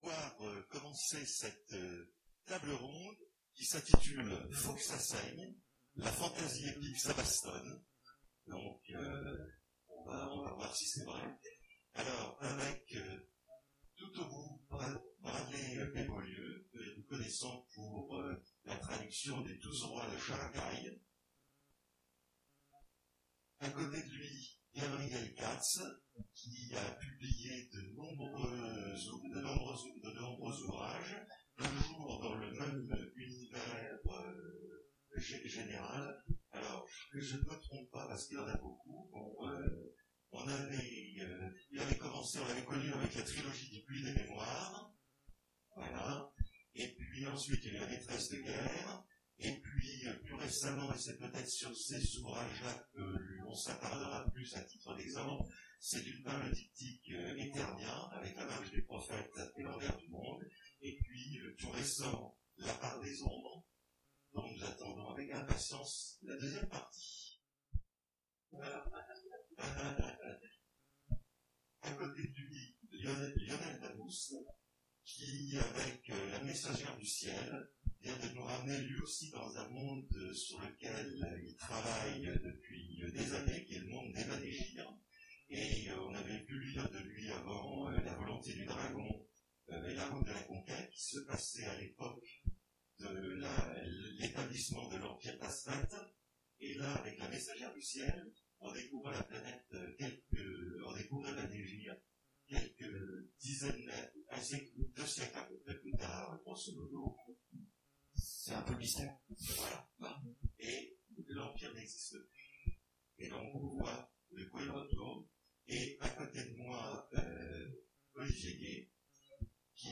Mots-clés Epique Fantasy Conférence Partager cet article